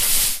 sounds_lava_cool.3.ogg